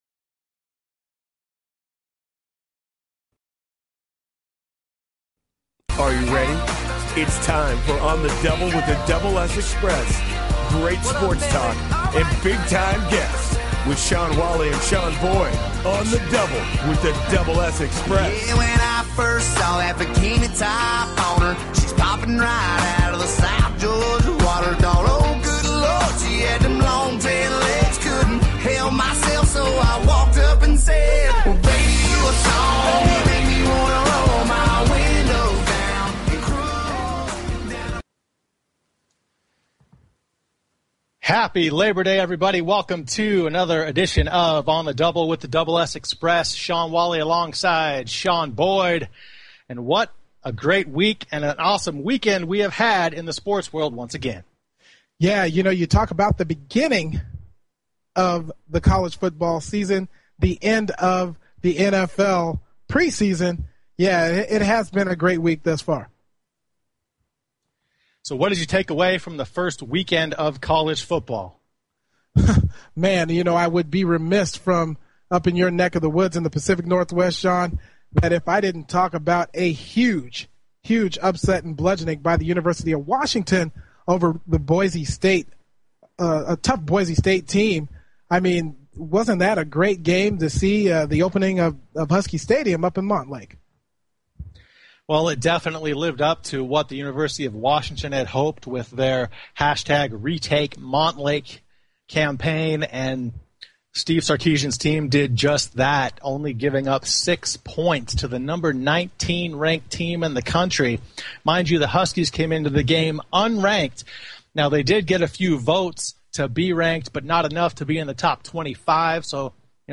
Talk Show Episode
sports talk show